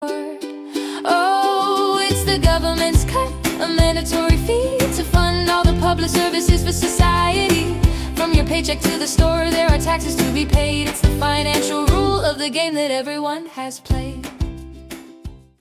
Accounting Karaoke System